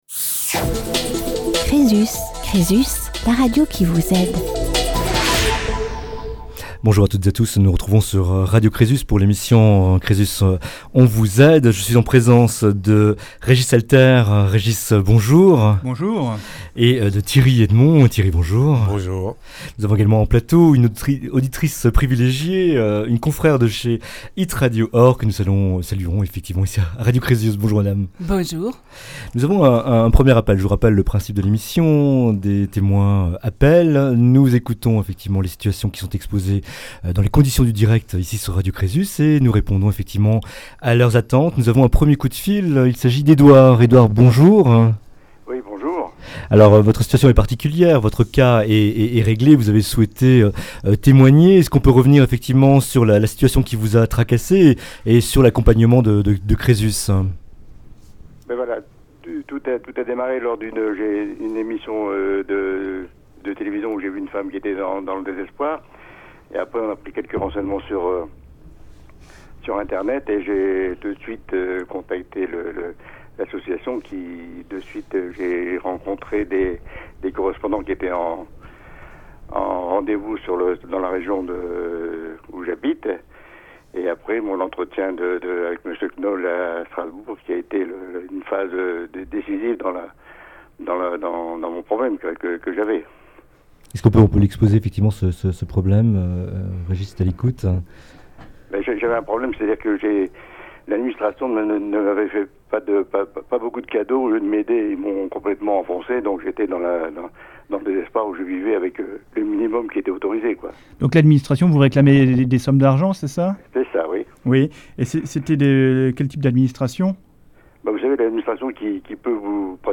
6 témoignages, 6 vies impactées par des évènements qui les ont plongées dans la difficulté financière.